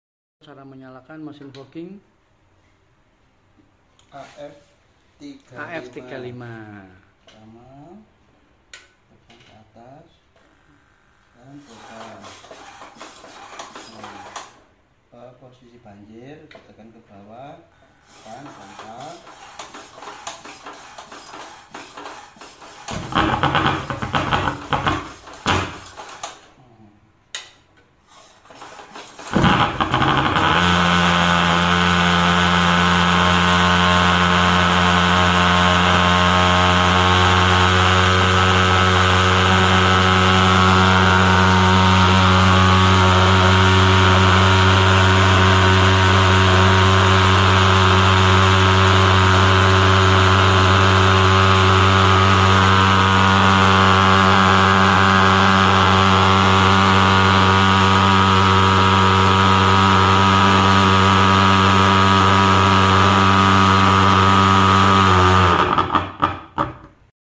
Tes Mesin Fogging Agrofogg sound effects free download
Tes Mesin Fogging Agrofogg AF 35